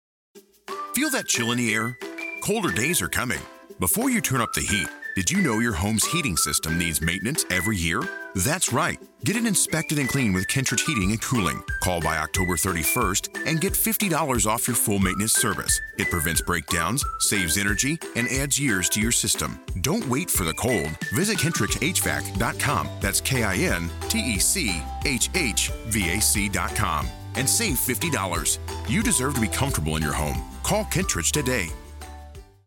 Young Adult
Middle Aged